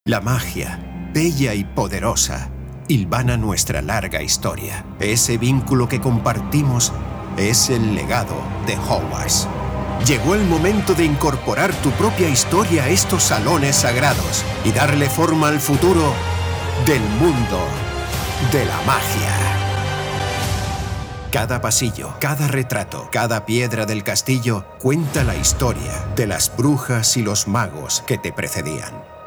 Movie and Game Trailer
castilian
demo trailer películas.wav